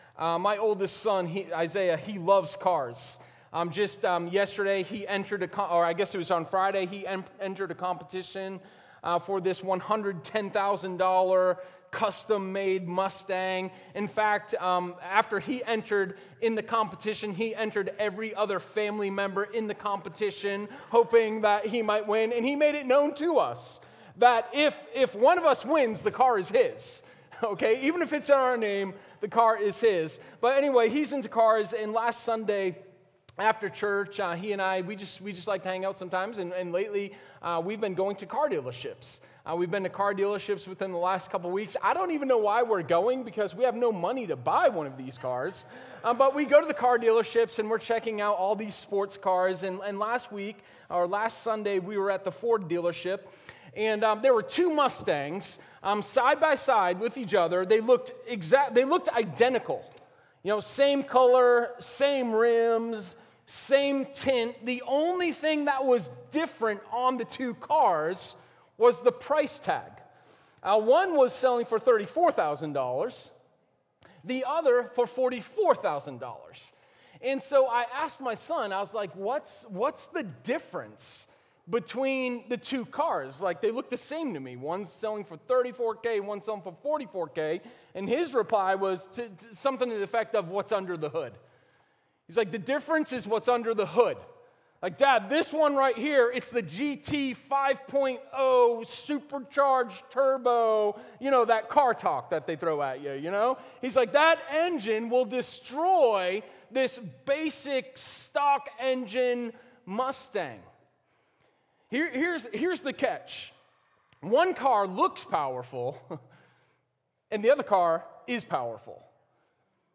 Sermon03_14_Wartime-Victory.m4a